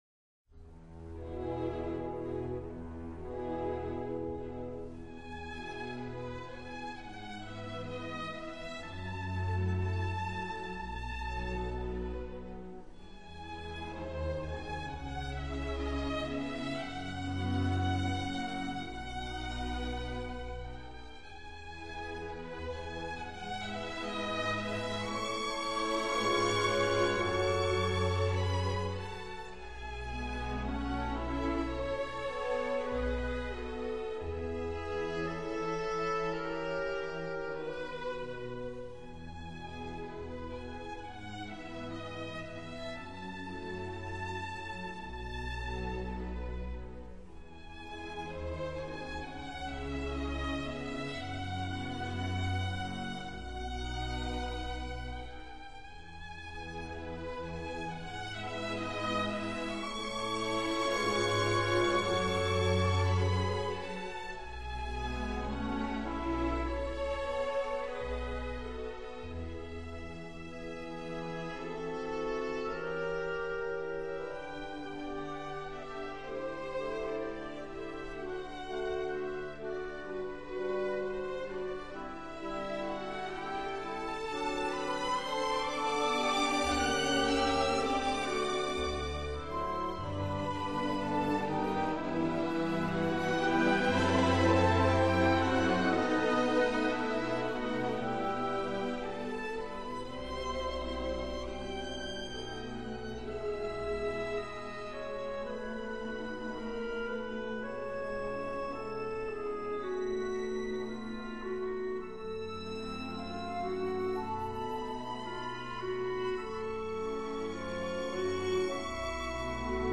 内  容： 世界古典名曲 Highlights